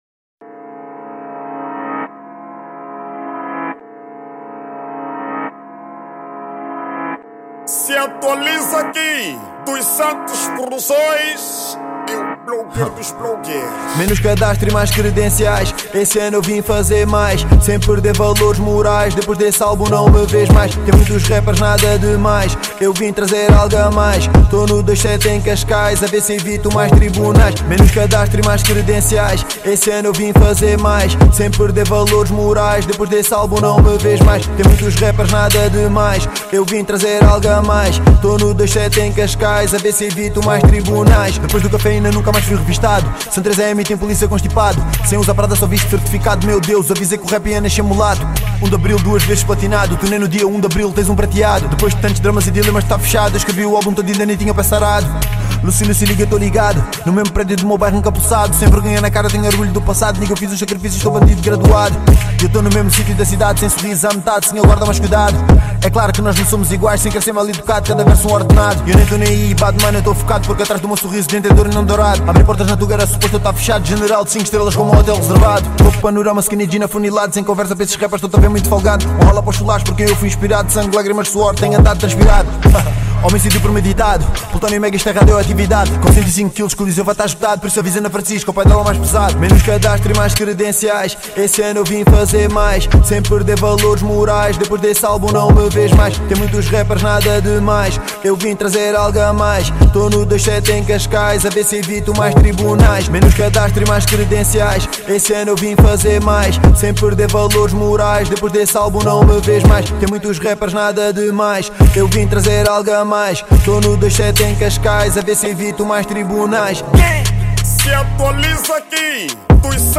ESTILO DA MÚSICA:  RAP/HIP HOP
MUSIC STYLE: Rap